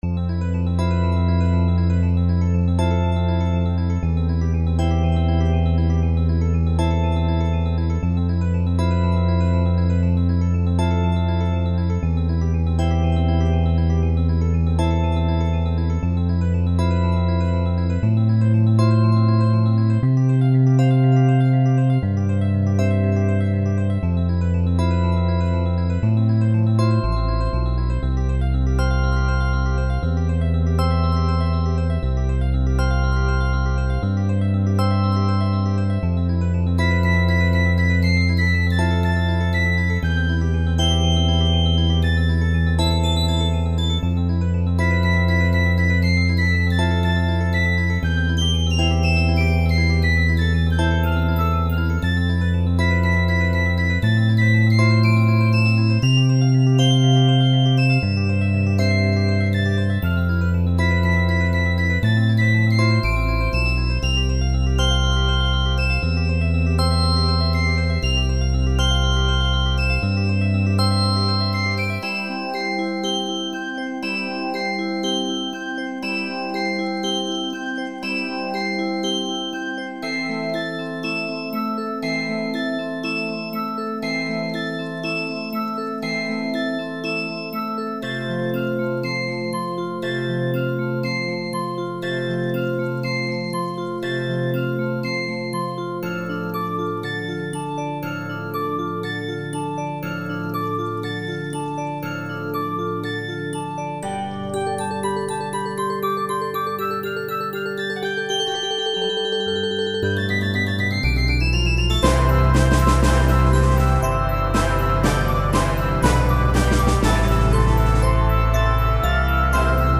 It sounds like Nobuo Uematsu's music, maybe trying to make a little tribute to that great composer.